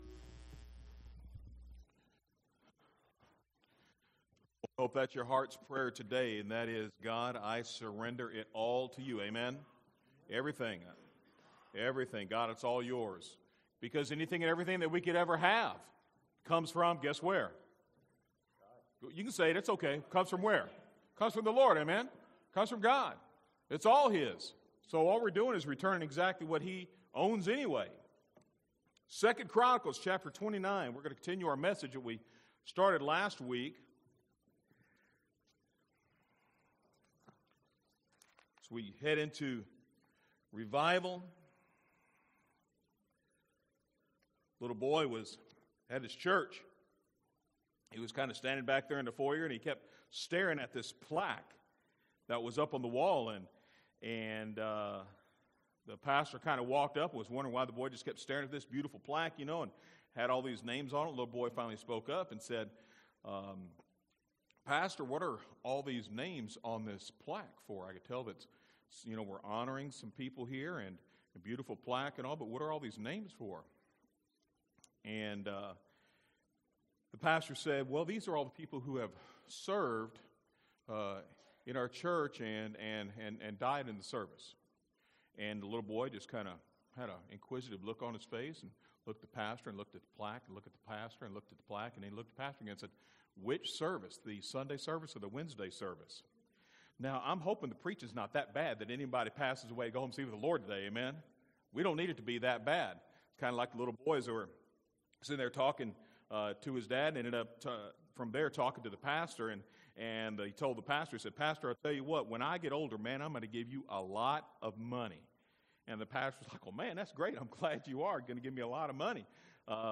From Series: "Individual Sermons"